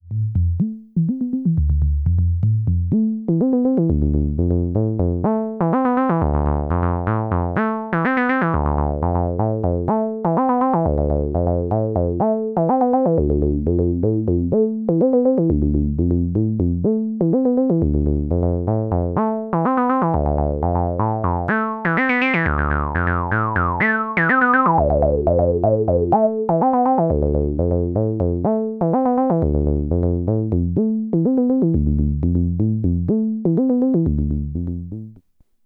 I guess for reference I should add on something similar that is SB-1 only. Nothing gain matched or anything fancy, just a little similar to the Muff take, but clean.